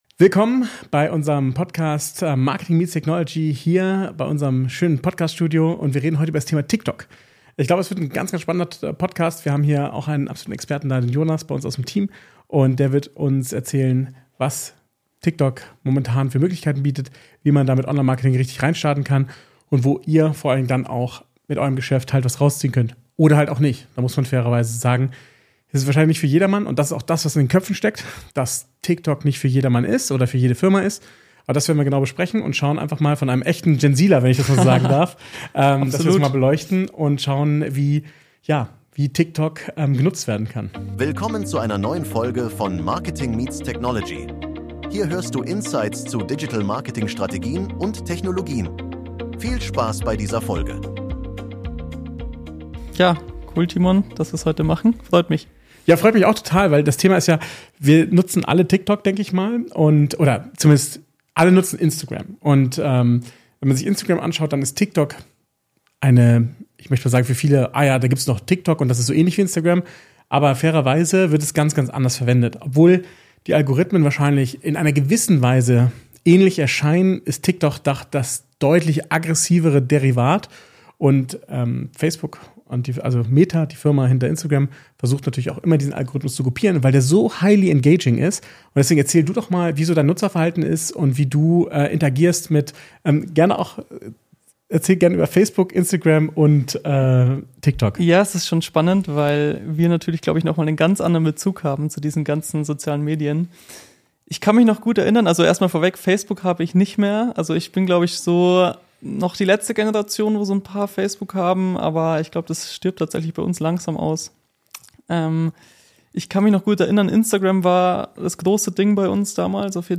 TikTok als Marketing-Booster – Chancen & Herausforderungen | Interview